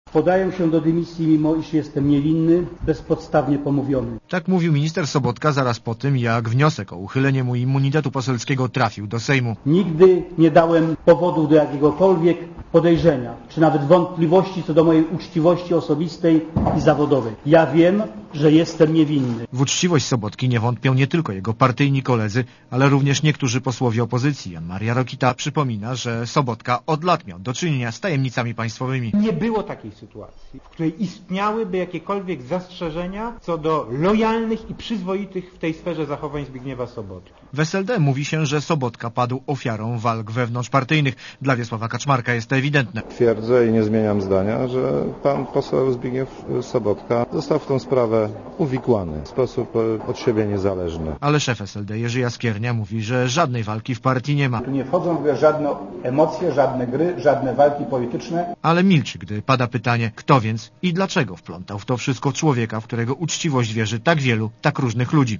Relacja reportera Radia Zet (256Kb)